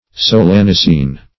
Search Result for " solanicine" : The Collaborative International Dictionary of English v.0.48: Solanicine \So*lan"i*cine\, n. [See Solanine .]
solanicine.mp3